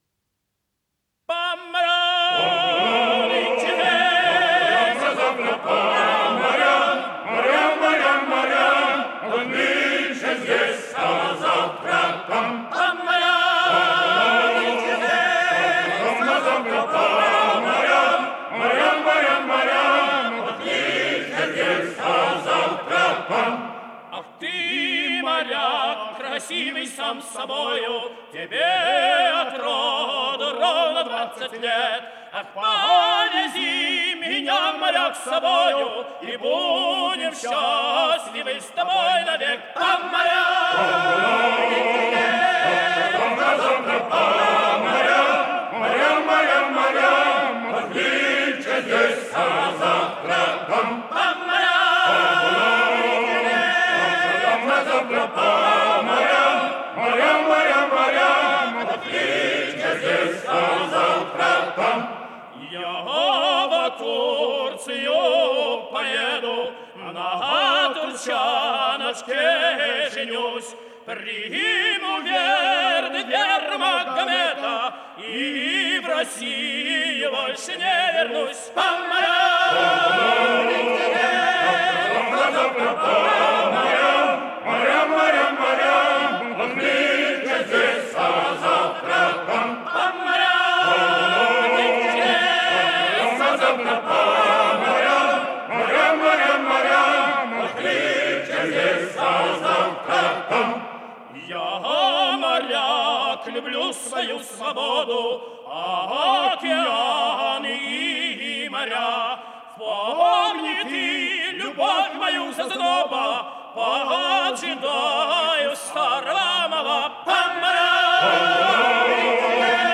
03-hor--s.jarova---po-moryam,-moryam,-moryam.mp3